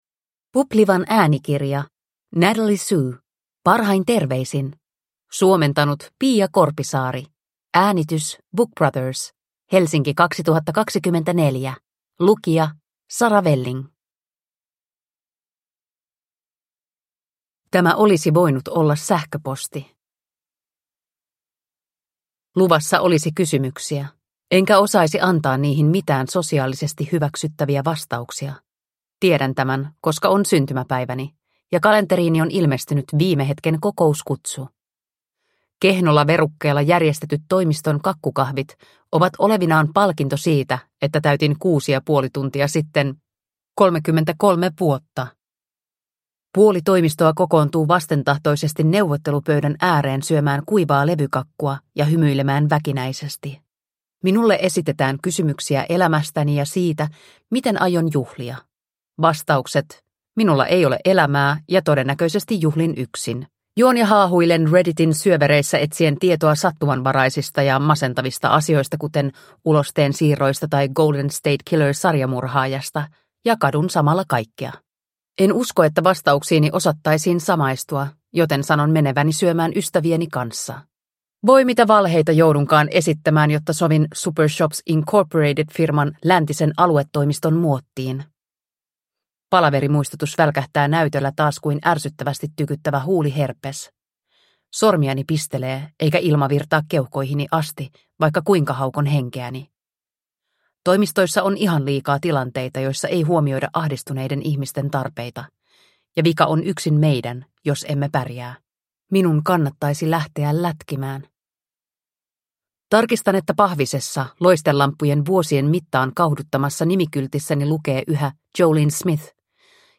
Parhain terveisin (ljudbok) av Natalie Sue